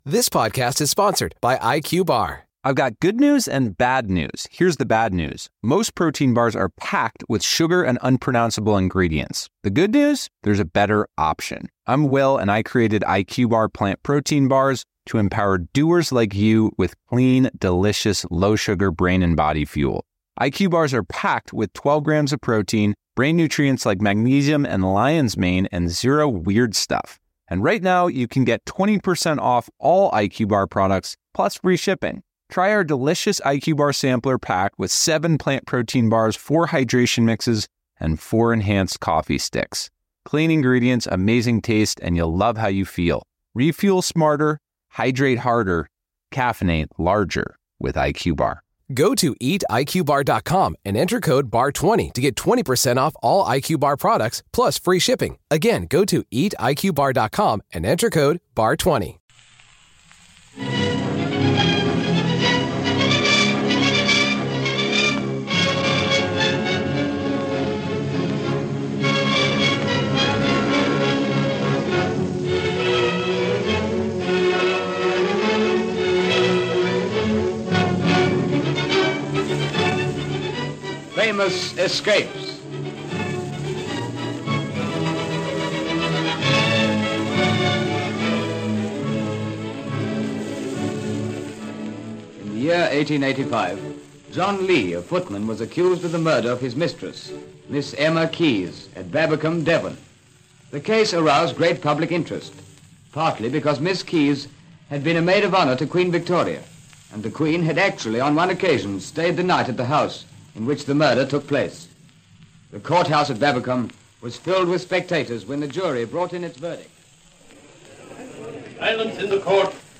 Famous Escapes was a captivating radio series produced in Australia around 1945.